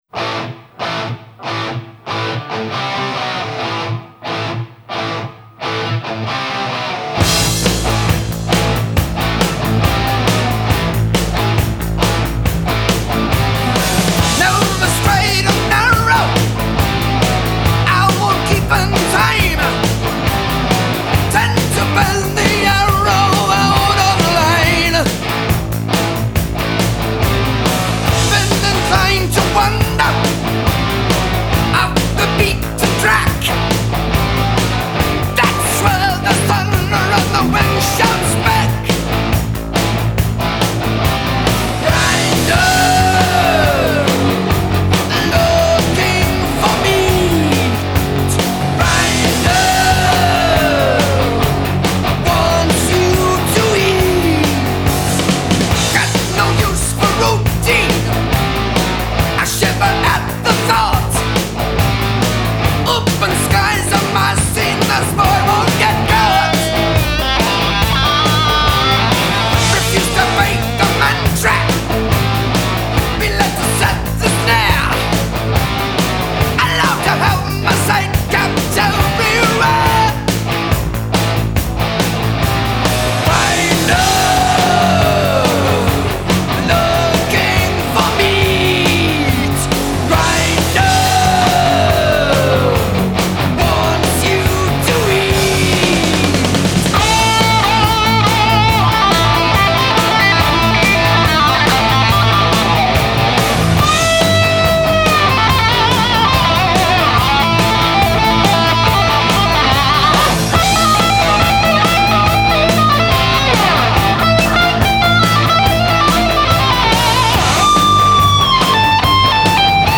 هوی متال